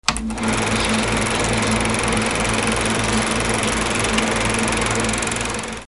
Suono proiettore pellicola cinematografica
Accensione e rumore motore meccanico proiettore pellicola cinematografica.